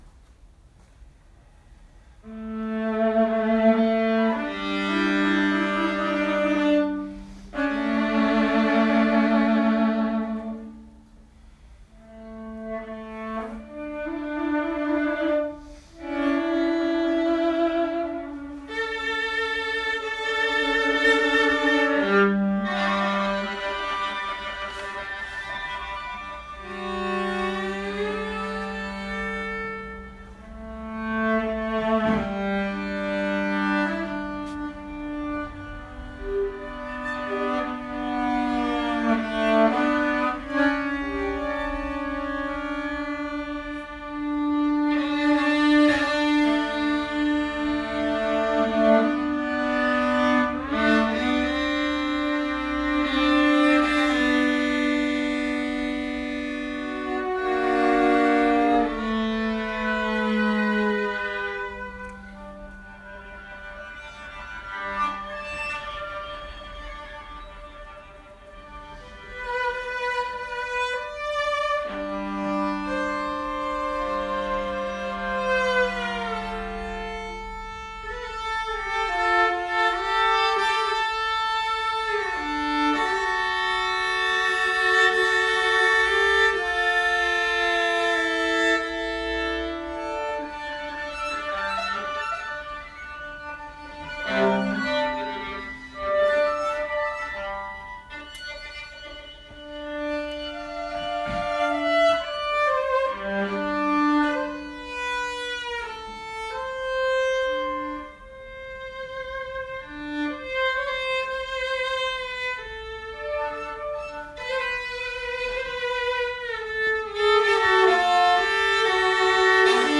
Viola Duo
violas